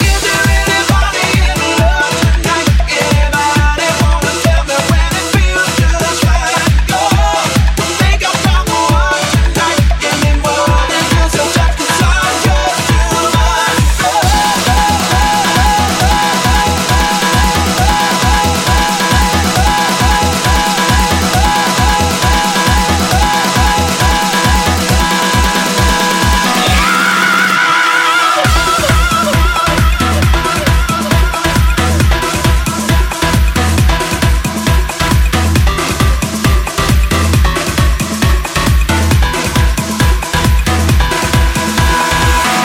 tribal - anthem - afro
Genere: tribal, jungle, anthem, afro, remix